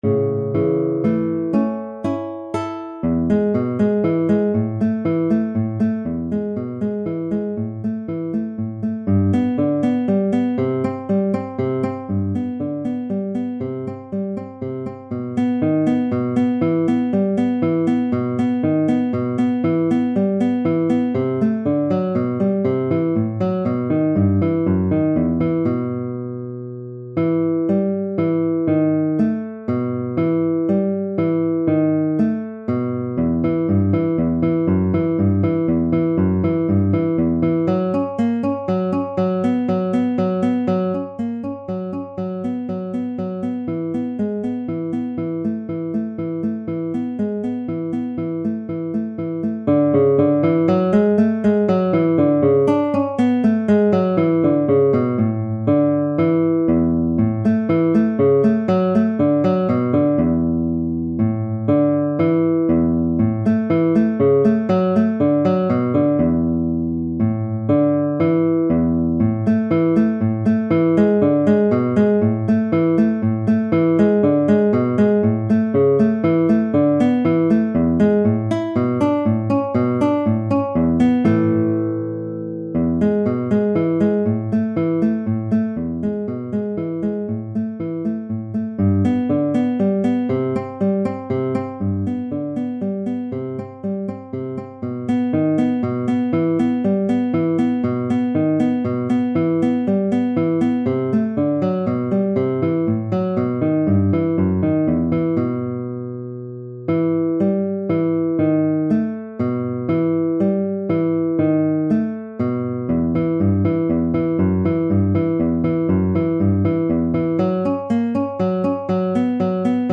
Guitar I B Section at 120 bpm